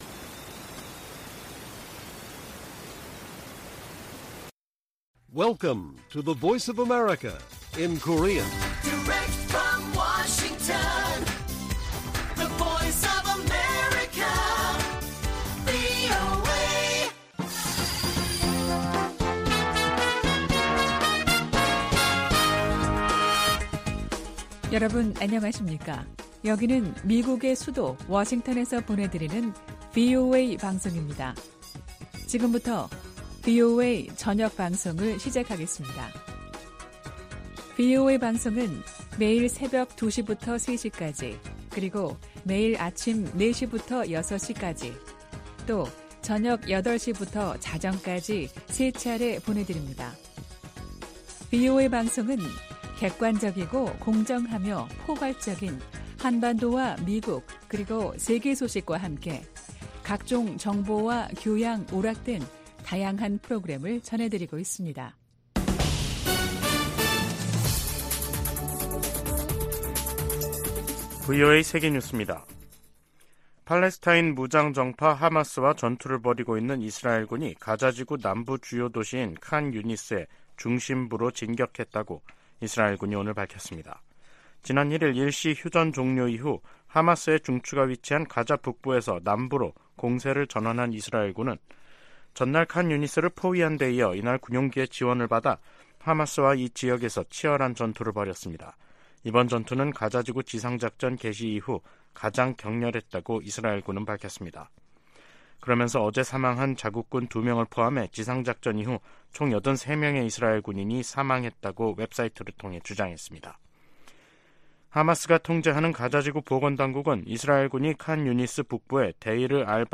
VOA 한국어 간판 뉴스 프로그램 '뉴스 투데이', 2023년 12월 6일 1부 방송입니다. 유엔총회가 북한 핵실험을 규탄하고 핵무기 폐기를 촉구하는 내용을 담은 결의 3건을 채택했습니다. 유럽연합(EU)이 북한과 중국, 러시아 등 전 세계의 심각한 인권 침해 책임자와 기관에 제재를 3년 연장했습니다. 북한 지도자가 딸 주애를 계속 부각하는 것은 세습 의지를 과시하기 위해 서두르고 있다는 방증이라고 한국 고위관리가 지적했습니다.